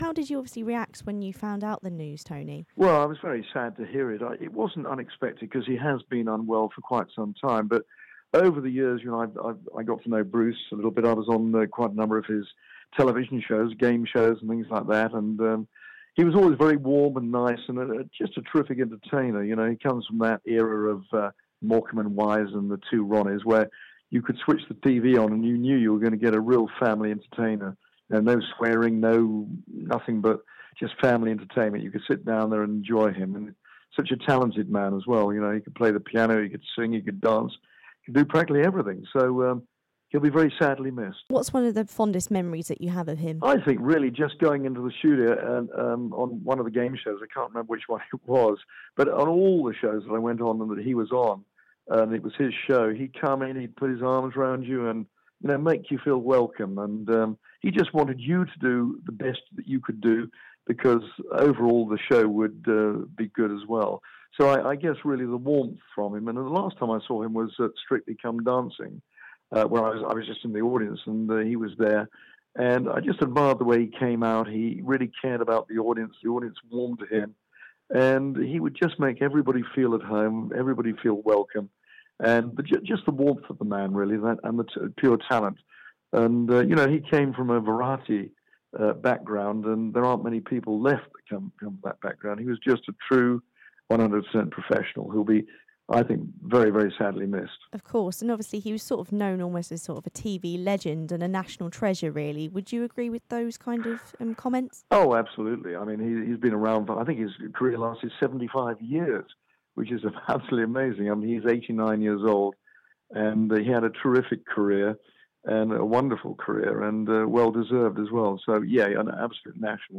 Fellow BBC colleague Tony Blackburn pays tribute to the late Sir Bruce Forsyth.